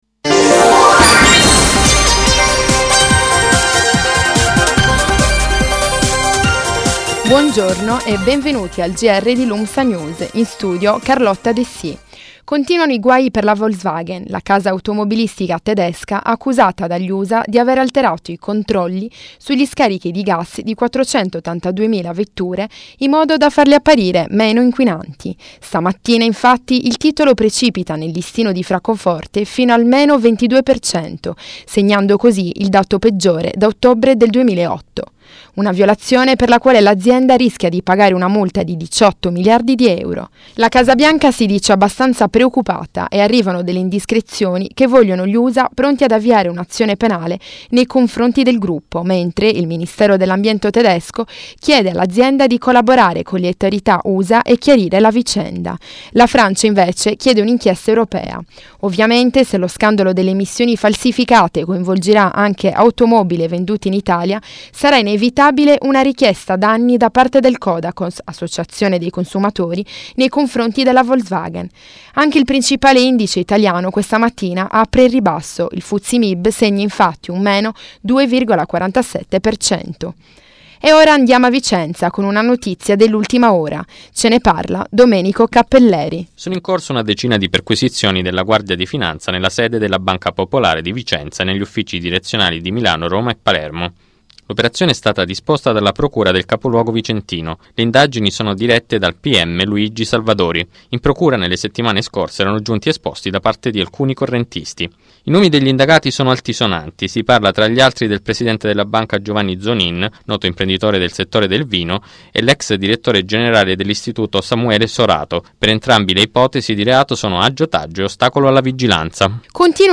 Gr ore 12 del 22 settembre 2015
In studio: